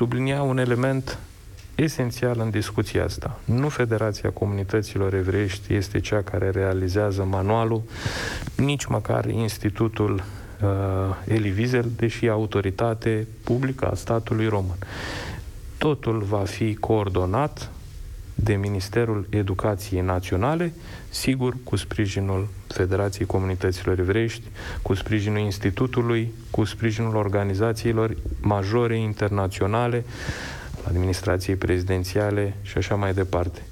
Silviu Vexler – Președintele Federației Comunităților Evreiești din România – a fost invitatul lui Cătălin Striblea în emisiunea „Deșteptarea României” la Europa FM.